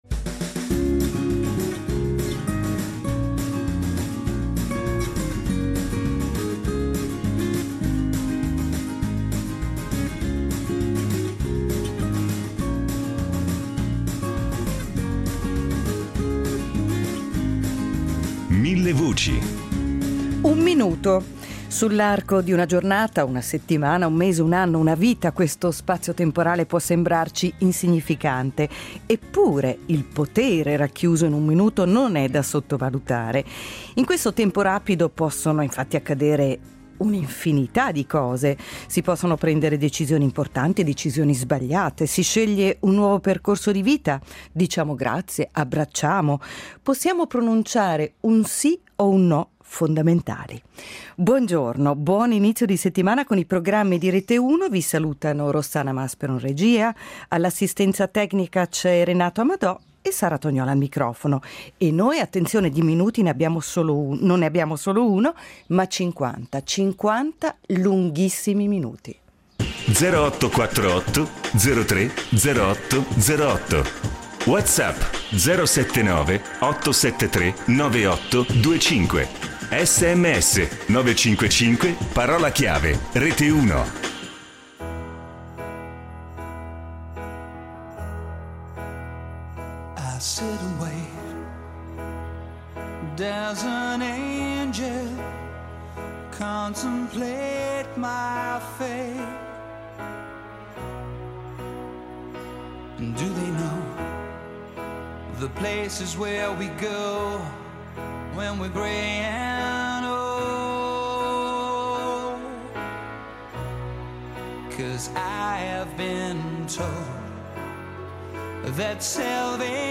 In studio anche lo psicologo